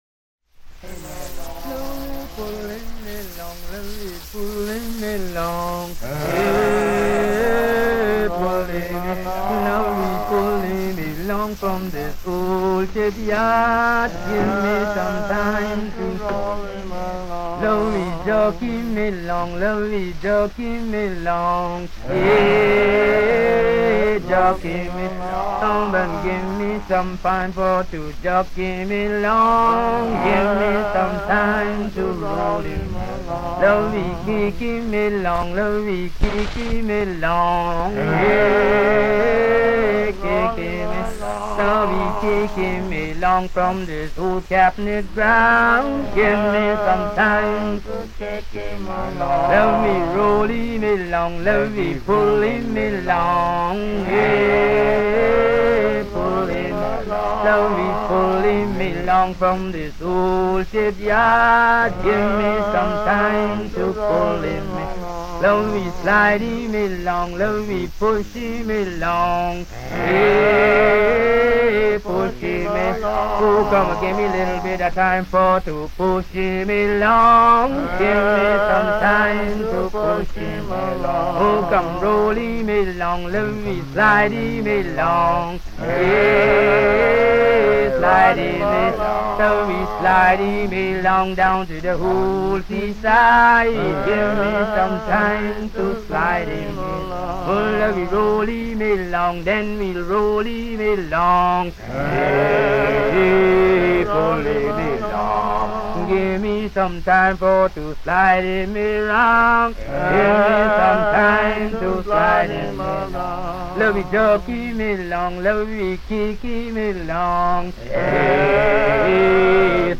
Bahamas 1935 - Vol 01 - Chanteys & anthems
Dalle registrazioni di Alan Lomax (anno 1935!) questi canti del mare e religiosi che provengono dall'arcipelago delle Bahamas